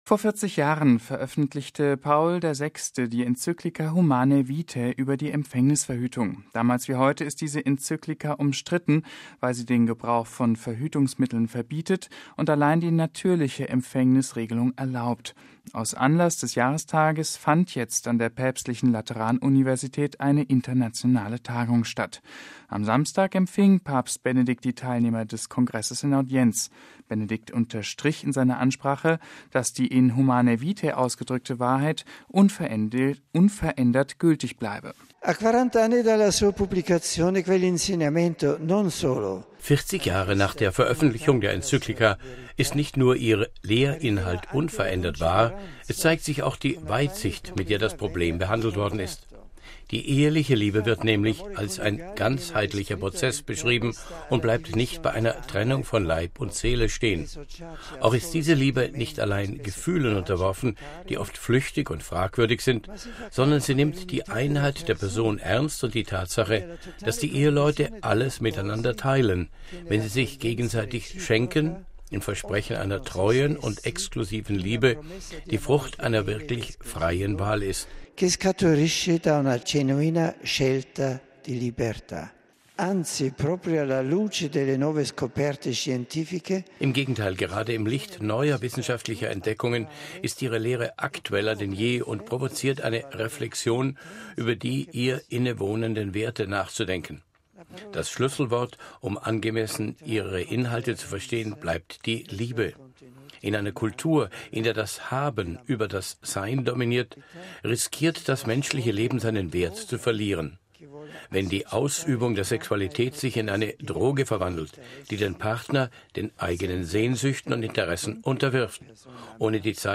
Aus Anlass des Jahrestages fand jetzt an der Päpstlichen Lateran-Universität eine internationale Tagung statt. Am Samstag empfing Papst Benedikt XVI. die Teilnehmer des Kongresses in Audienz: